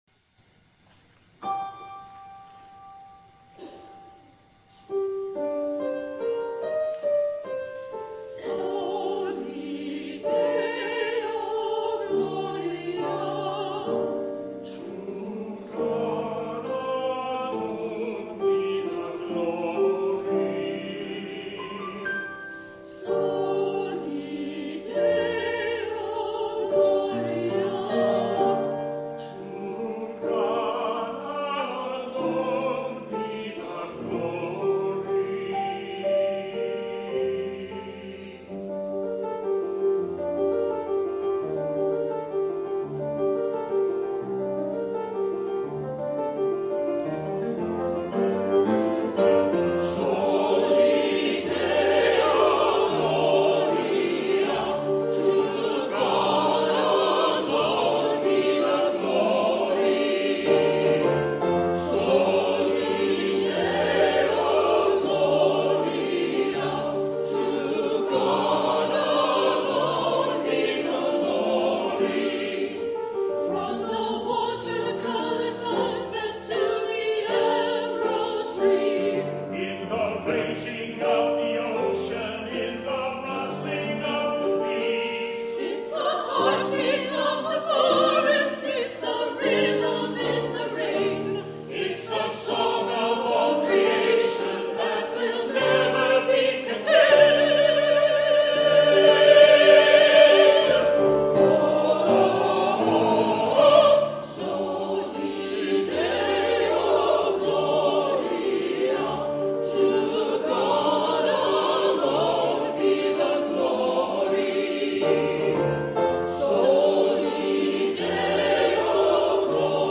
The Second Reformed Chancl choirs sings "Soli Deo Gloria" by Larry Bryant